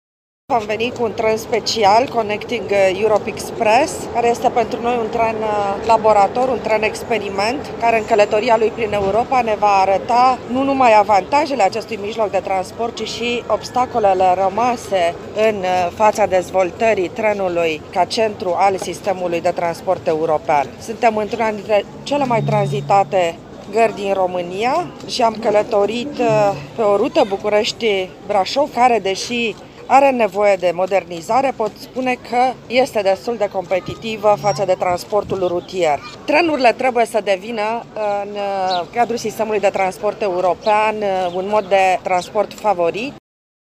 Garnitura a fost special creată pentru a marca Anul European al Căilor Ferate și pentru a sublinia importanța acestui tip de transport pentru Uniunea Europeană. Cu trenul Connecting Europe Express a călătorit, azi, spre Brașov și comisarul european pentru transporturi, Adina Vălean: